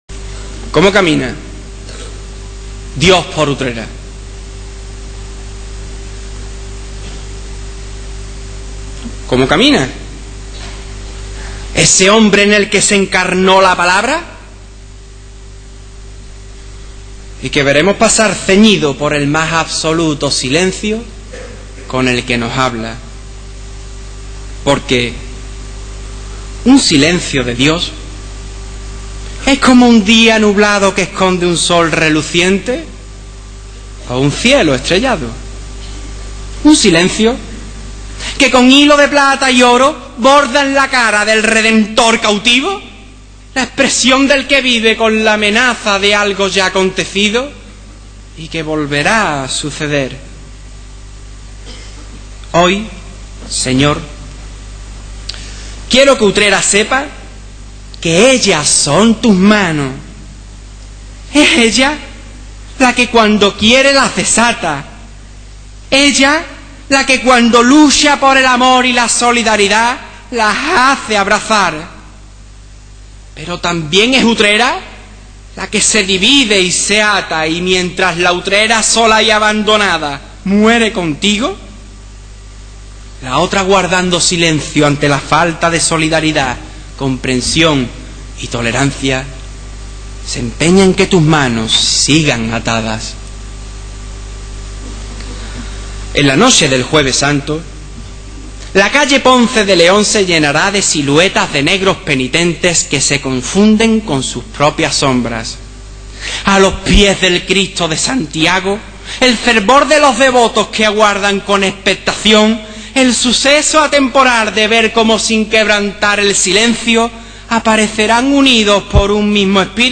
El pasado Domingo 28 de marzo de 2004 se celebró el Pregón de la Semana Santa en el Teatro Municipal Enrique de la Cuadra de Utrera
pregon_cautivo2004.wma